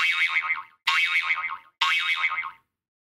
Category: iPhone Ringtones